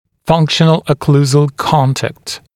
[‘fʌŋkʃ(ə)n(ə)l ə’kluːzəl ‘kɔntækt][‘фанкш(э)н(э)л э’клу:зэл ‘контэкт]функциональный окклюзионный контакт